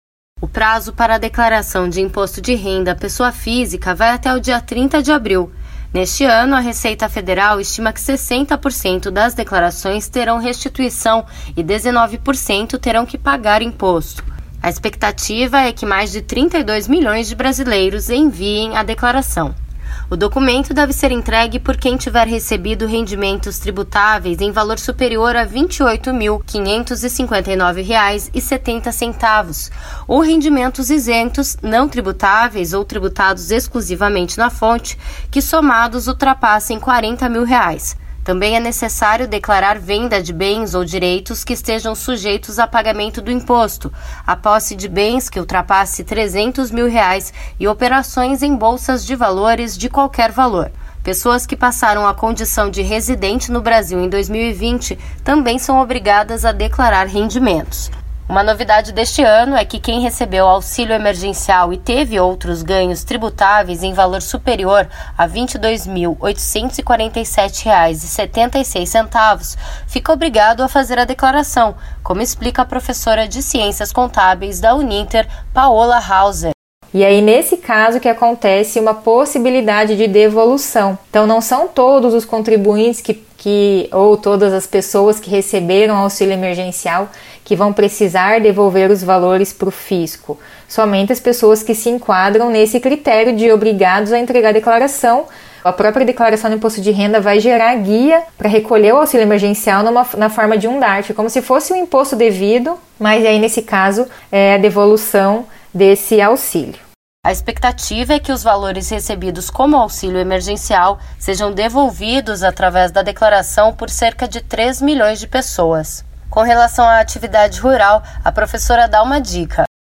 Cerca de 3 milhões de contribuintes vão precisar devolver o auxílio emergencial no Imposto de Renda. Veja se esse é o seu caso na reportagem.